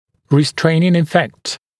[rɪ’streɪnɪŋ ɪ’fekt][ри’стрэйнин и’фэкт]сдерживающий дефект, сдерживающее влияние